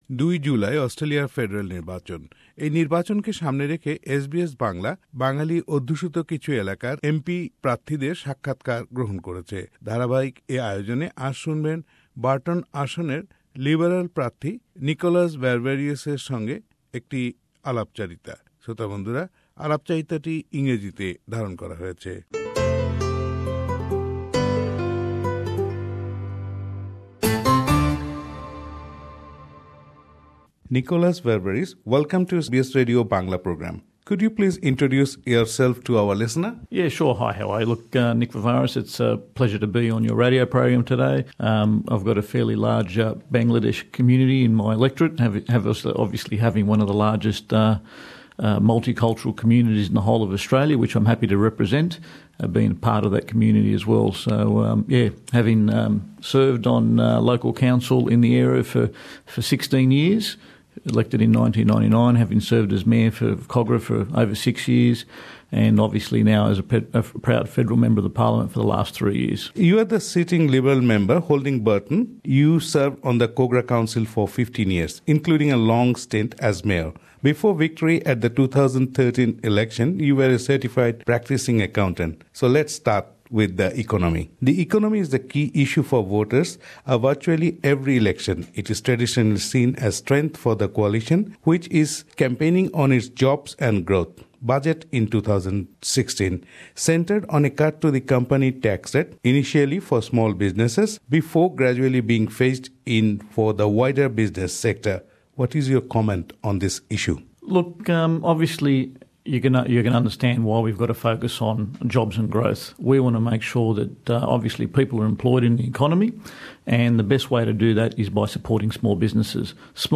Interview with NIckolas Varvaris